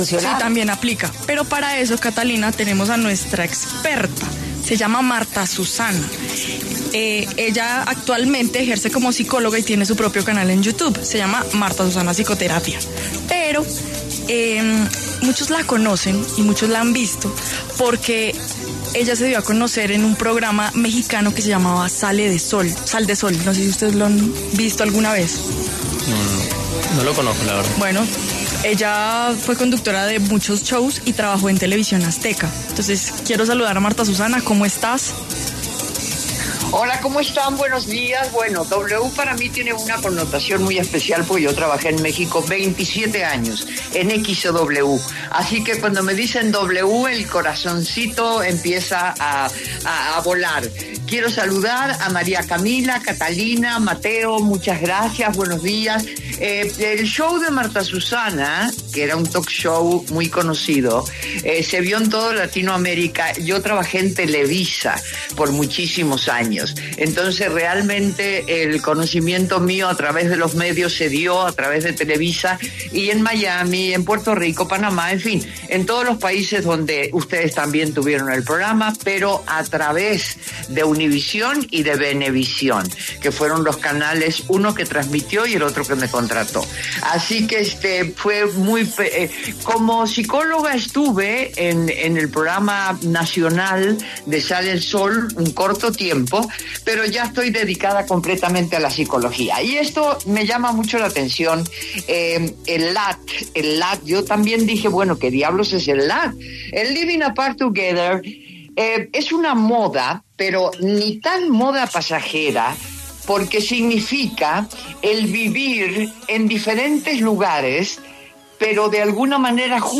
Marta Susana, psicóloga y conductora de talk shows, estuvo en los micrófonos de W fin de semana para hablar sobre Living Apart Together, la nueva dinámica de las parejas para mantener una relación estable.